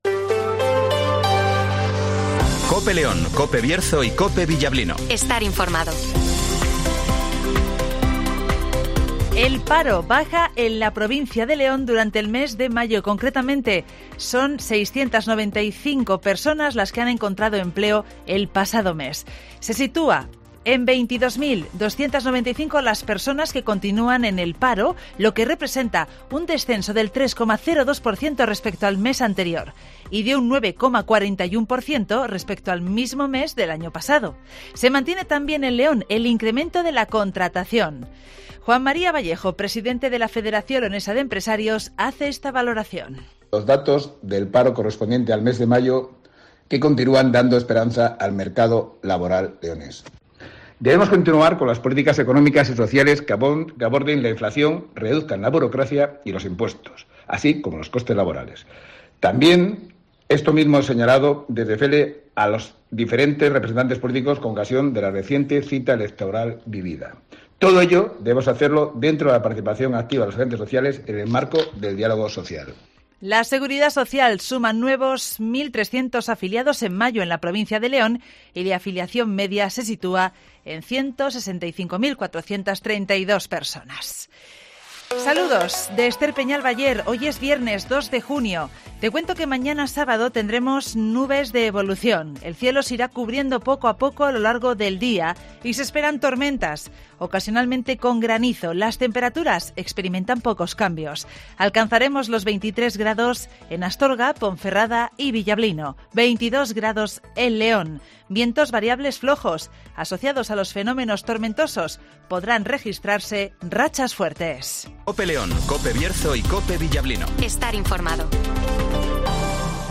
Informativo Mediodia